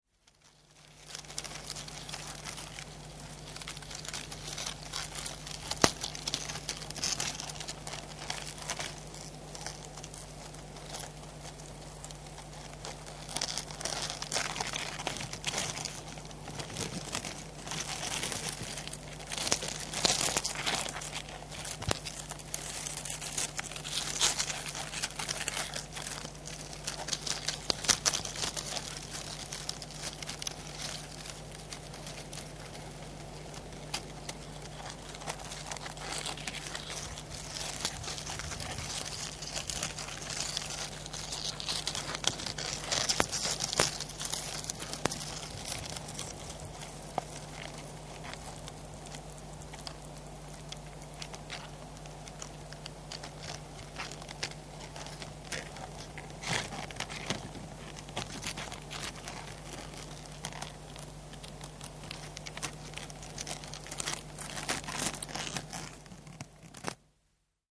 Шуршание кокосовых листьев под шагами рака-отшельника